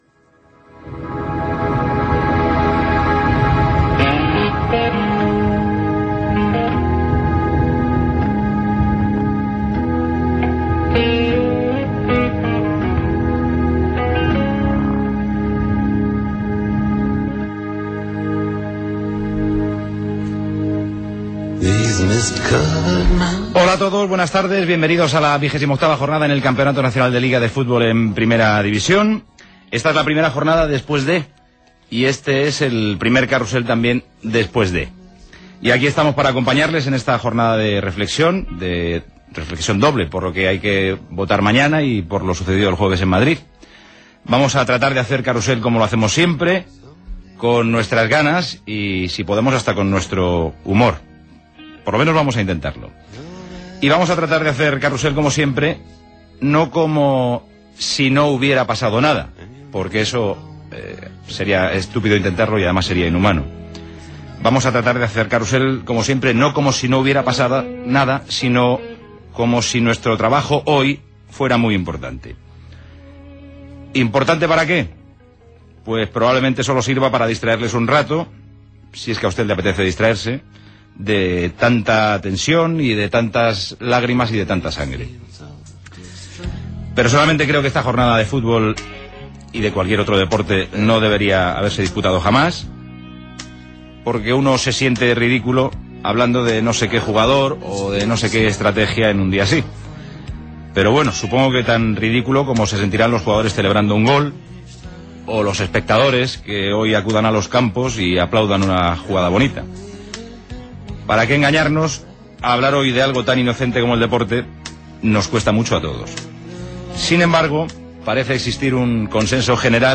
Paraules inicials de Paco González dos dies després dels atemptats terroristes a diversos trens de rodalia a Madrid i el dia abans de les eleccions generals espanyoles. Indicatiu de la candea i careta del programa
Esportiu